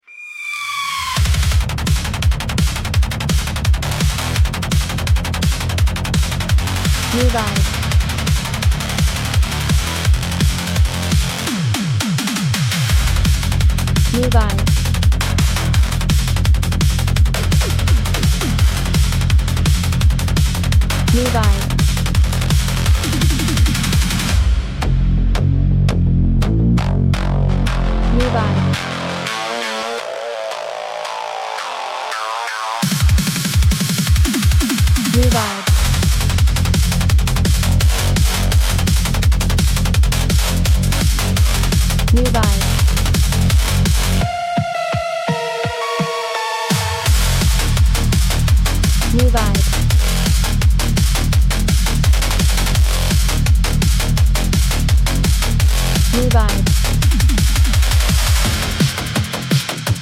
Genre: Dynamic, hard beat